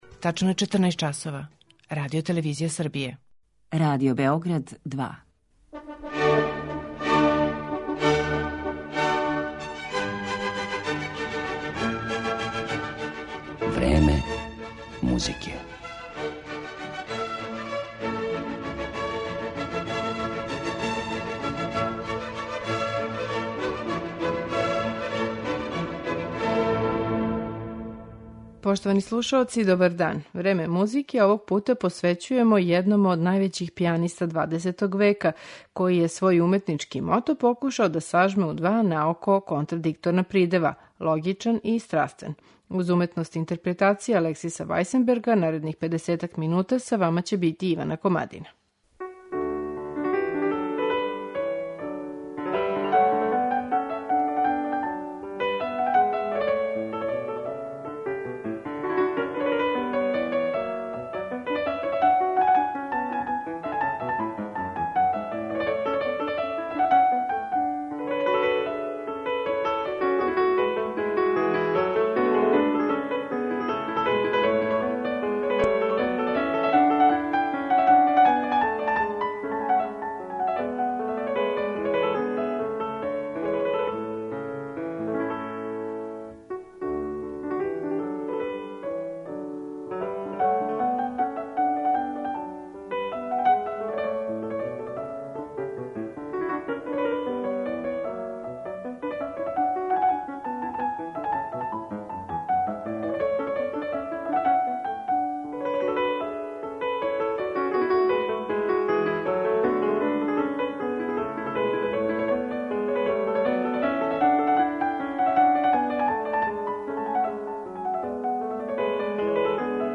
Данашње Време музике посветили смо овом великану 20. века. Вајсенбергову особену уметничку личност осветлићемо не само његовим сећањима на младалачке дане већ и интерпретацијама дела Јохана Себастијана Баха, Клода Дебисија, Фредерика Шопена, Доменика Скарлатија, Игора Стравинског и Карла Чернија.